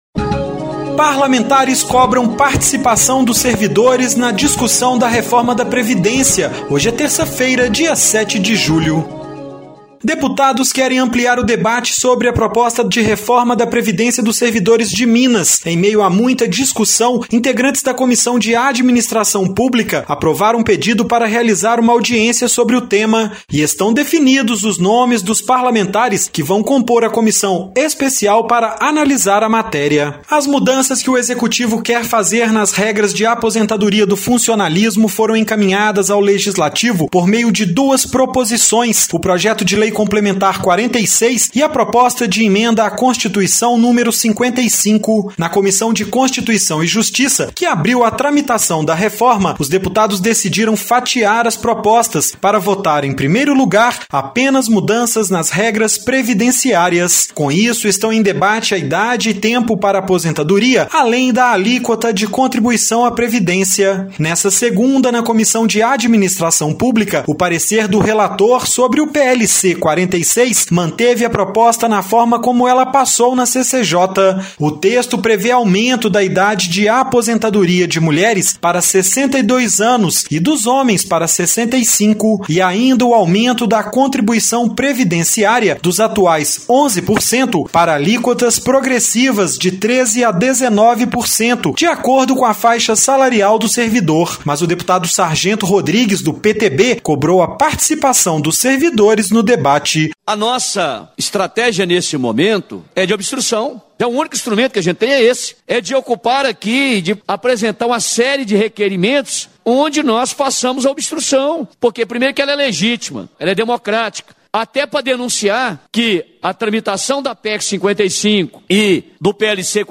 Boletim da ALMG - Edição n.º 4896